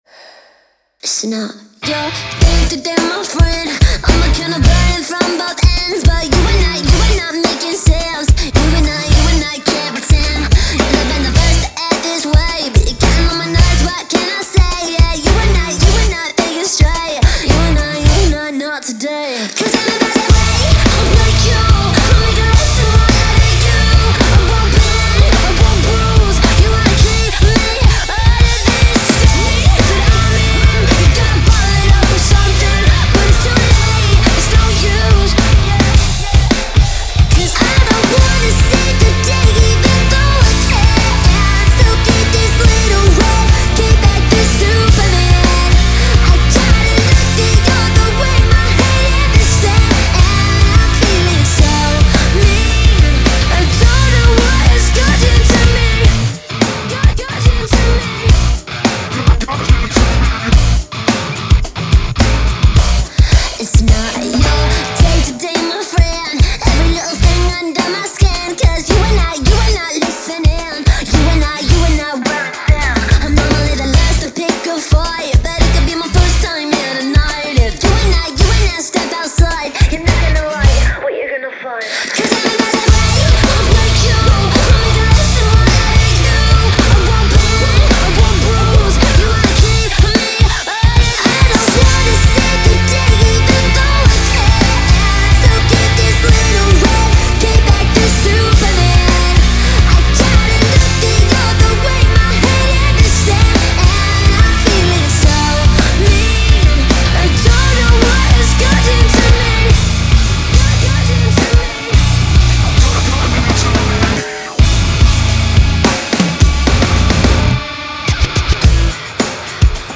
BPM107-107
Audio QualityPerfect (High Quality)
Full Length Song (not arcade length cut)